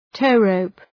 Shkrimi fonetik {‘təʋ,rəʋp}